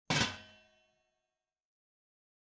Kitchen Pot Lid Close Wav Sound Effect #3
Description: The sound of gently placing a lid on the kitchen pot
Properties: 48.000 kHz 16-bit Stereo
Keywords: kitchen pot, pan, lid, metallic, metal, cover, cooking, put, place, placing
pot-lid-close-preview-3.mp3